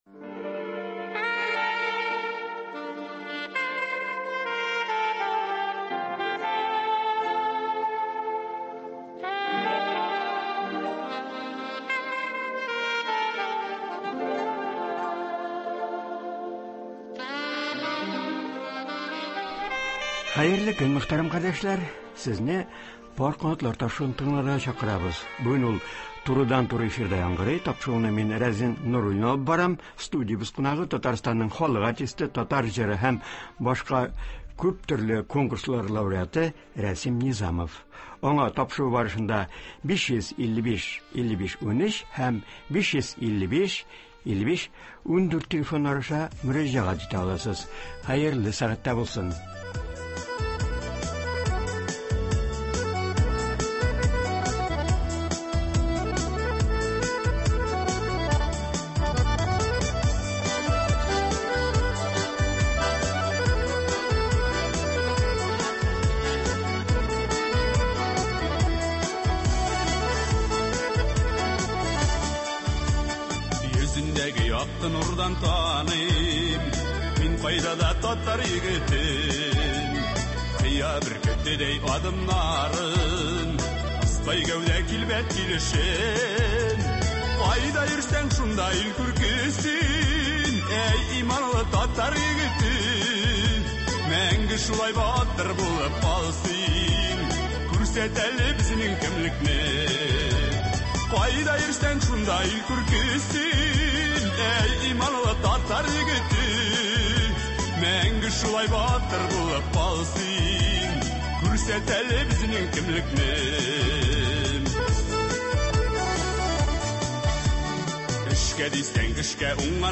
Ул турыдан-туры эфирда катнашып, сәнгатебезгә мөнәсәбәтен белдерәчәк, коронавирус уңаеннан кертелгән чикләүләр вакытында артистларның нинди иҗади эшләр белән шөгыльләнүләре турында сөйләячәк.